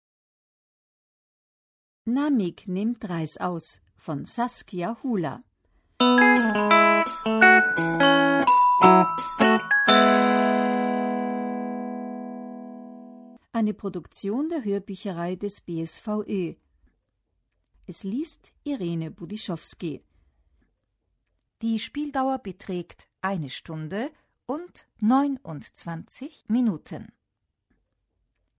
Großer Lesemarathon mit der Hörbücherei!